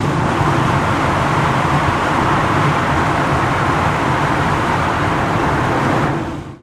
Car Wind | Sneak On The Lot